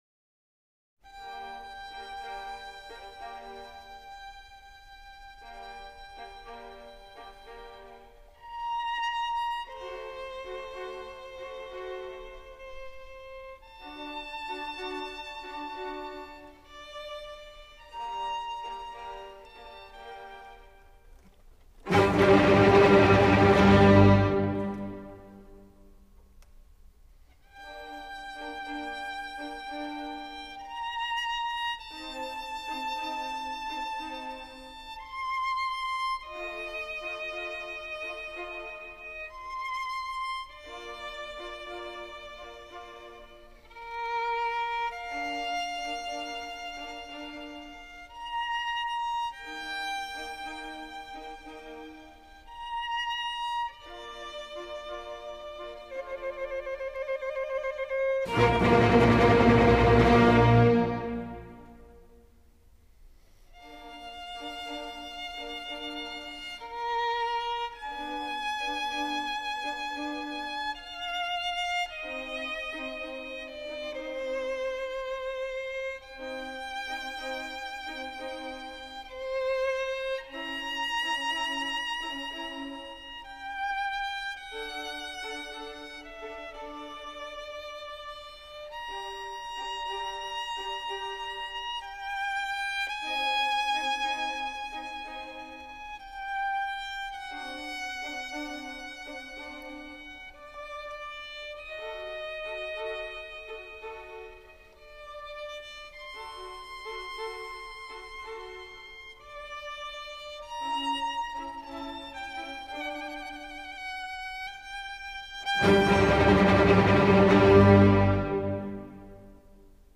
вы только послушайте. allegro non molto: